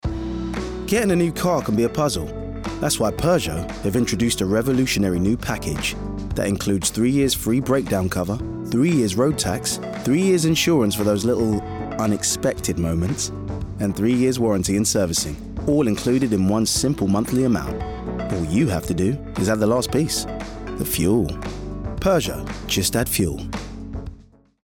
20/30's London, Fresh/Funny/Charismatic